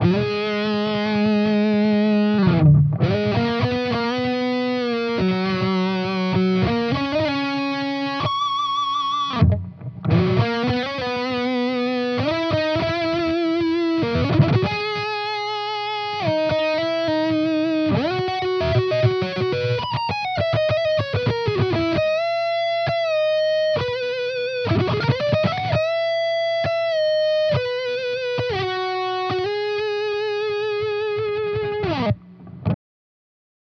Here are a few quick demos of my custom overdrive/distortion pedals for your listening pleasure.
Blue pedal with boost and EQ
I used a Sennheiser MD441-U microphone on-axis, edge of speaker cap, and about an inch away from the grill.
blue-pedal-with-boost-and-eq.wav